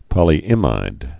(pŏlē-ĭmīd)